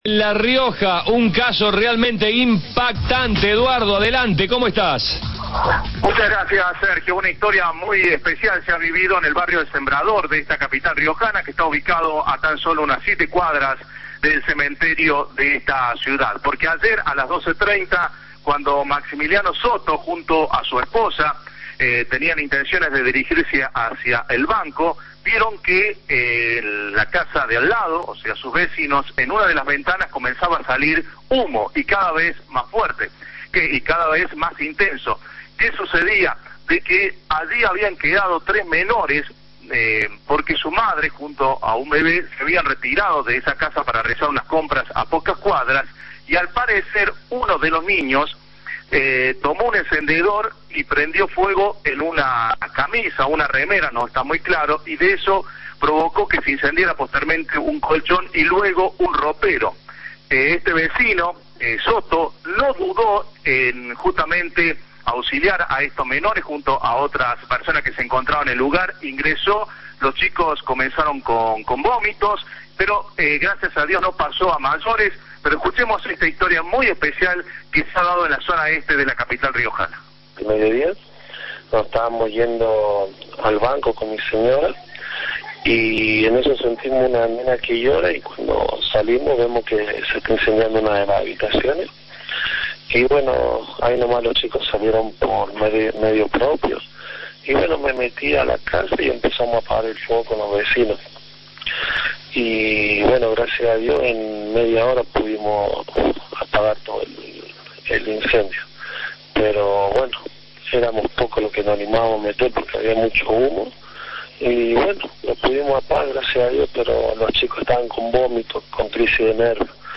(Informe de Cadena 3)